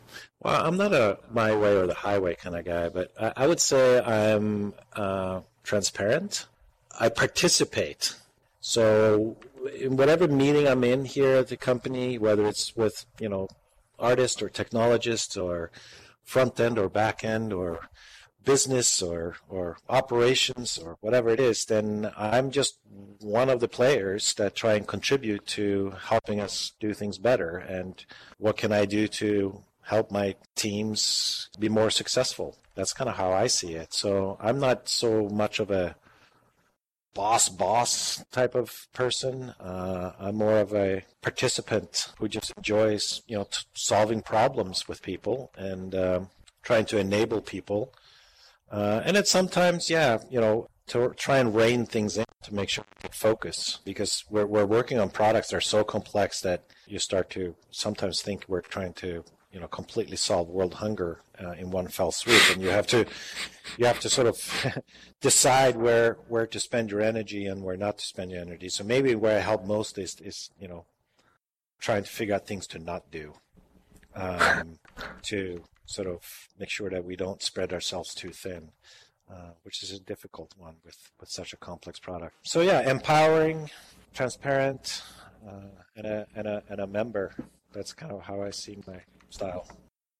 answering questions from hosts